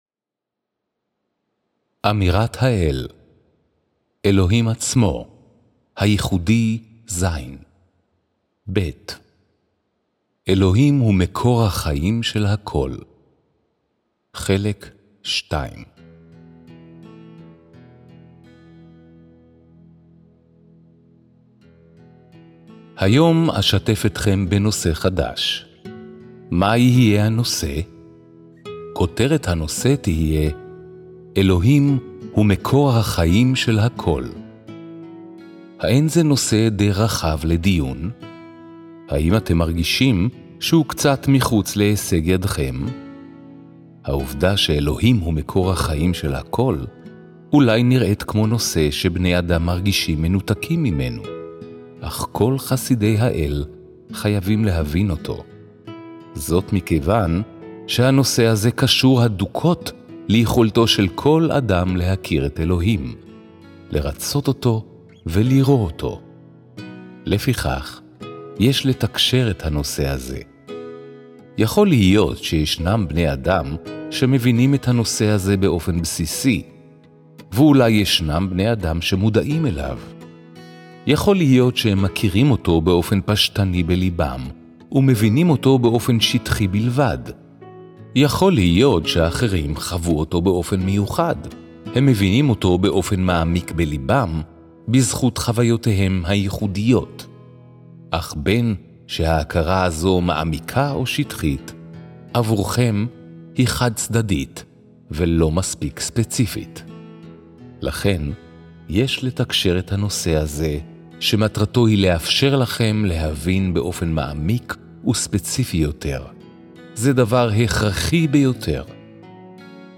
Album: Hebrew Readings